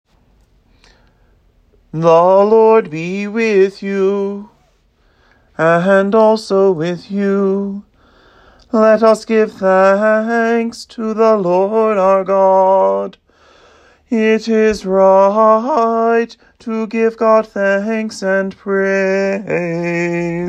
Below you will find a draft bulletin with audio files to listen and practice singing along as St. Hilda St. Patrick observes a principal feast with considerably more chanting.